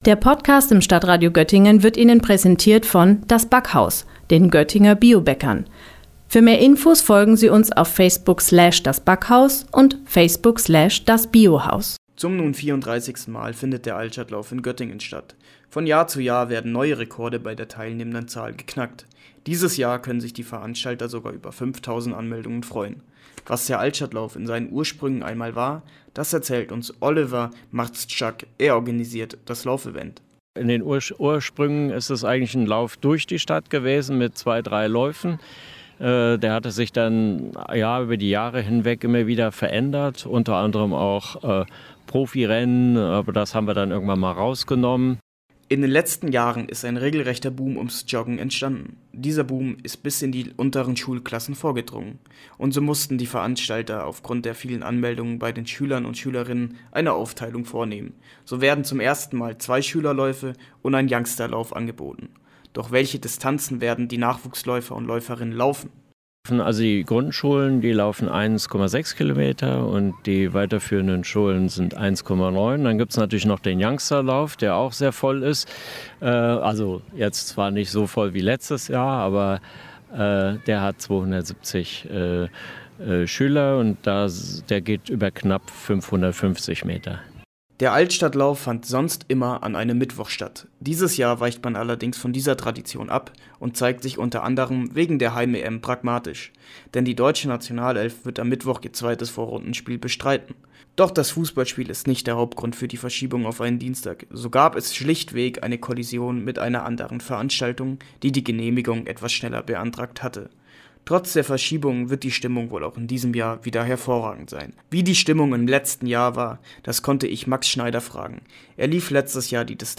Bericht